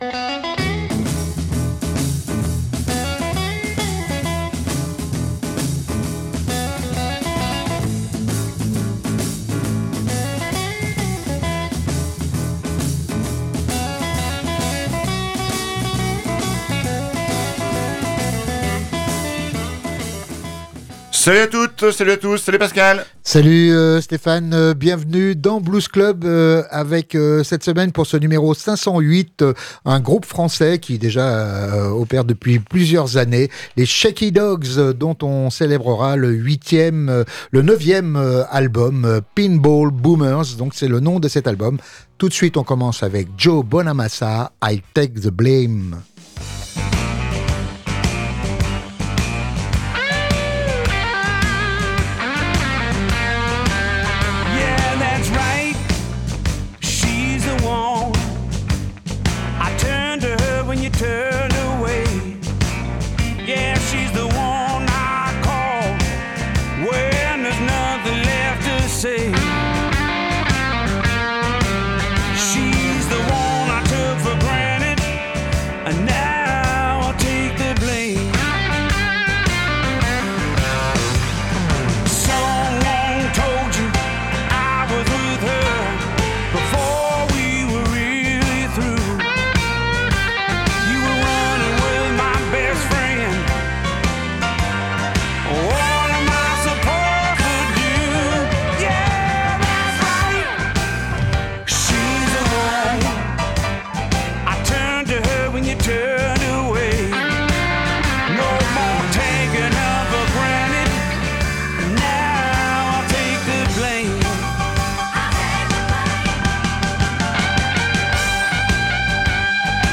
De quoi se laisser emporter par leur Fiesta Blues’n’Roll, sans perdre pour autant un regard critique sur la société.